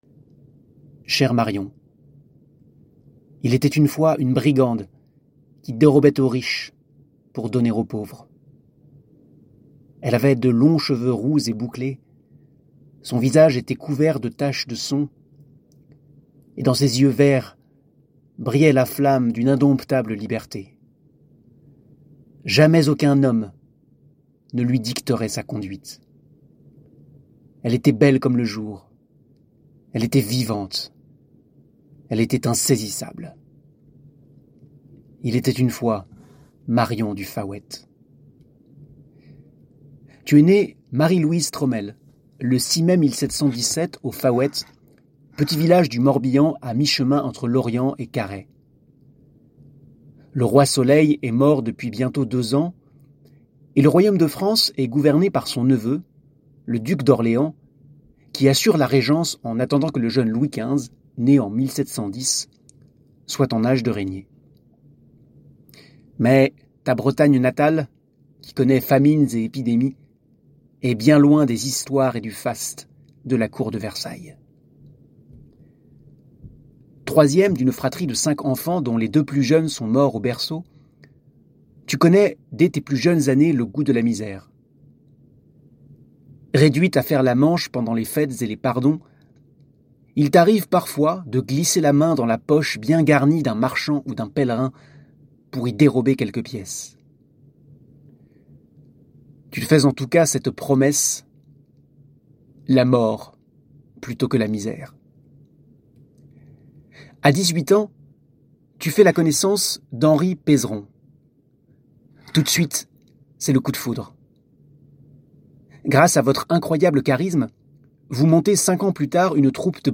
marion-du-faouet-la-robin-des-bois-de-bretagne-avec-musique.mp3